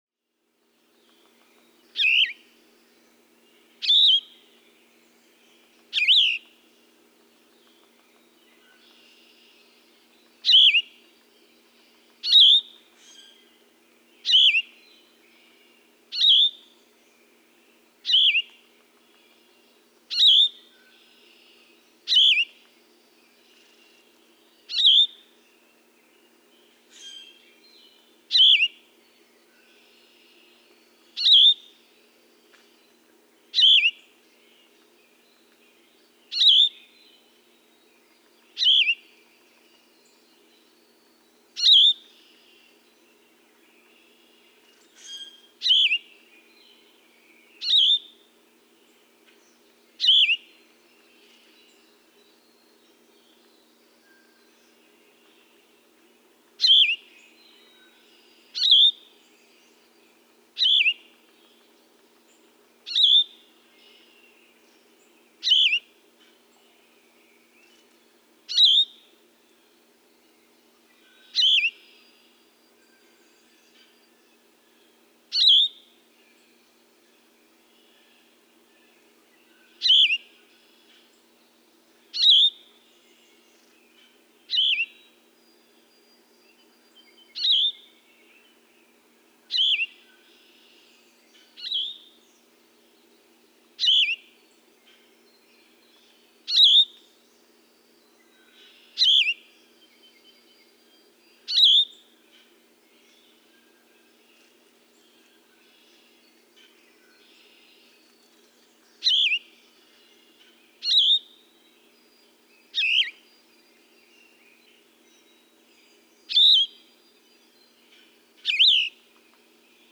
Yellow-throated vireo
Two song packages occur in this example, A B C F and D E; details in book's text.
Atlanta, Michigan.
435_Yellow-throated_Vireo.mp3